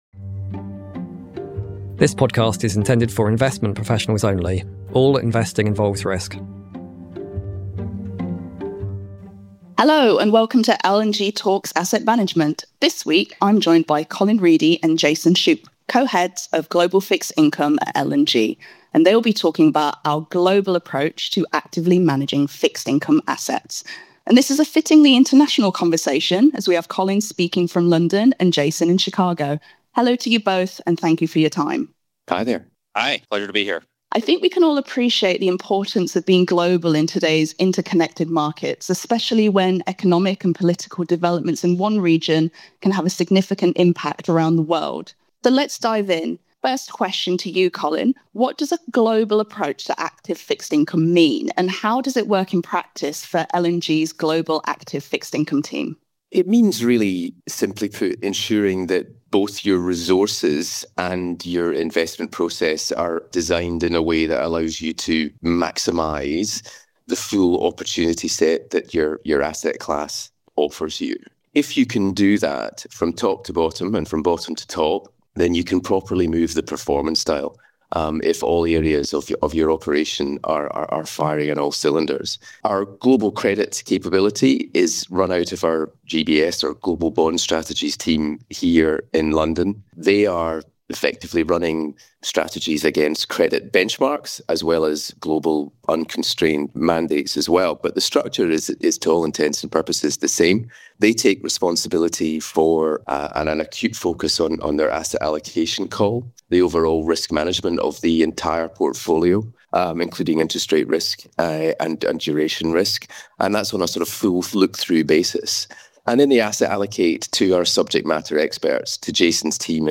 had a transatlantic conversation on the benefits of thinking and acting globally